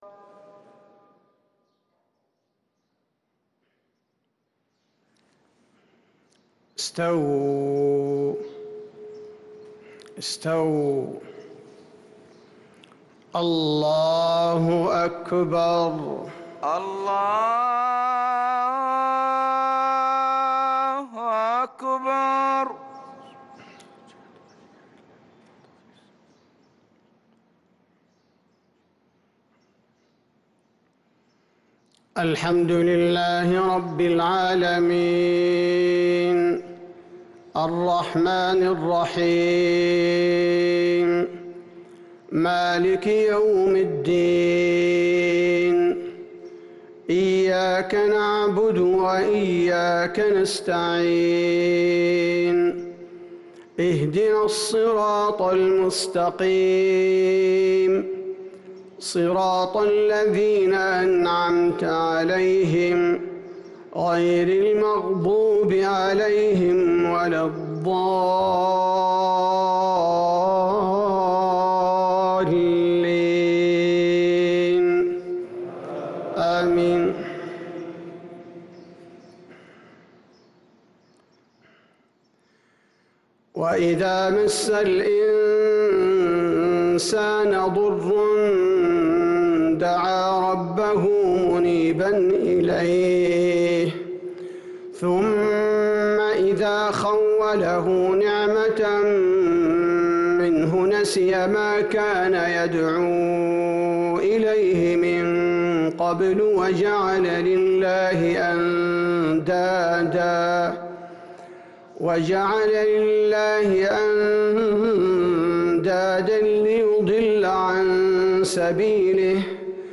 فجر ٢-٩-١٤٤٣هـ من سورة الزمر | Fajr prayer from surah az-Zumar 3-4-2022 > 1443 🕌 > الفروض - تلاوات الحرمين